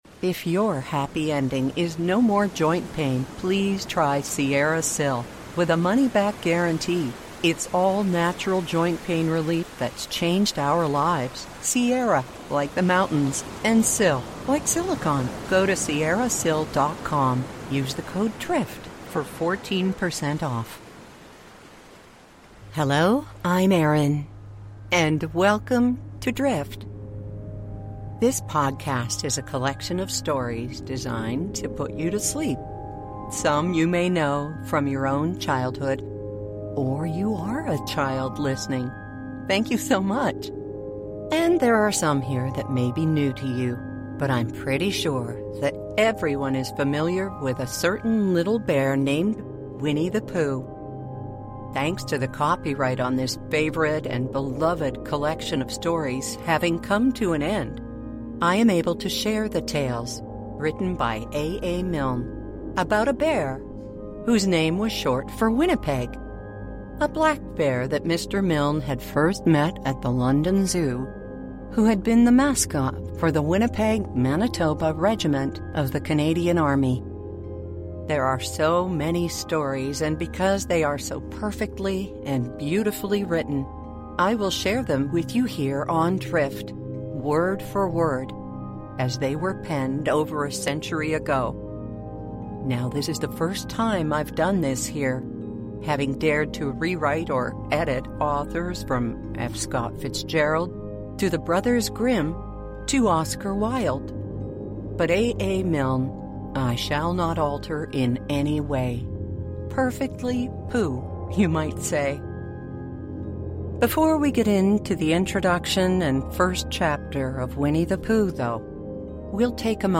Winnie-the-Pooh's gentle stories are told from the heart in a gentler time, and promise to delight you as you drift off to sleep.
Sleep Stories